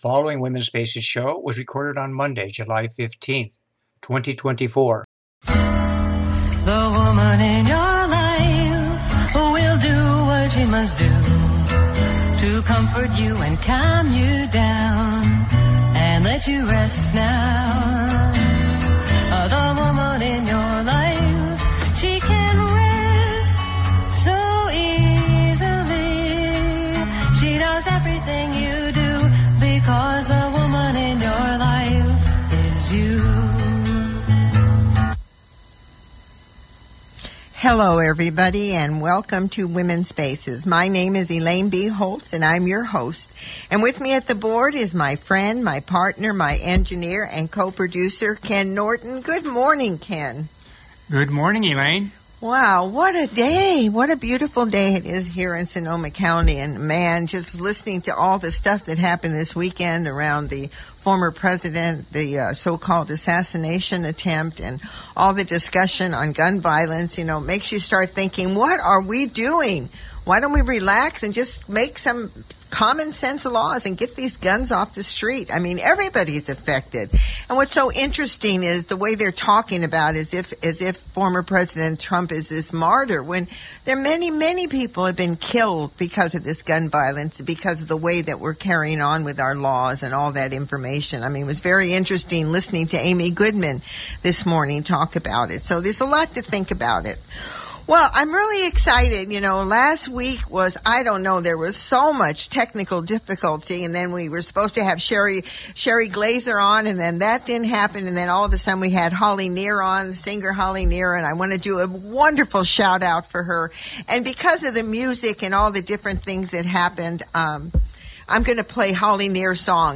Original Radio Show ID: WSA240715 Listen to the Show on the Mp3 Player below
Feminist, comedian, actor, author and Tarot reader.